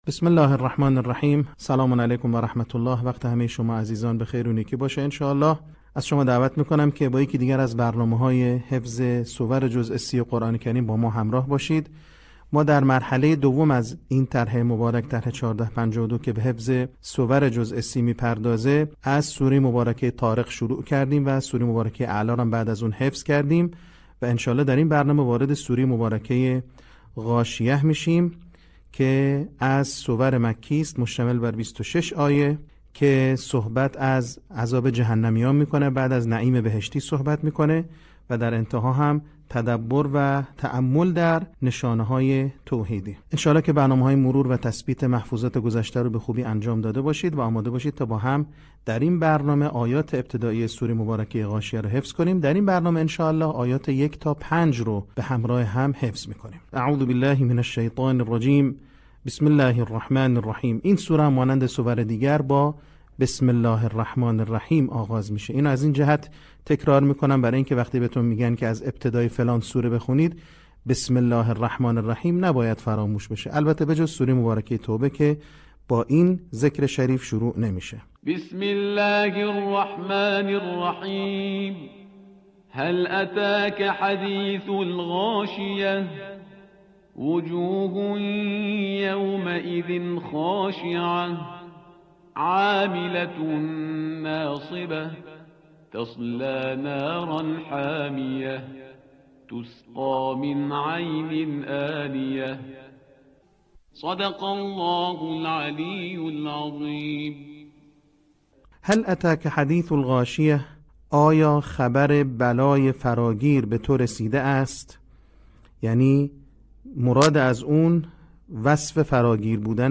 صوت | آموزش حفظ سوره غاشیه